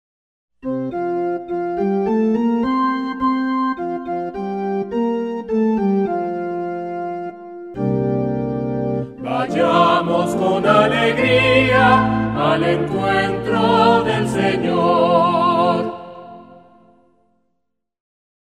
SALMO RESPONSORIAL Del salmo 121 R. Vayamos con alegría al encuentro del Señor.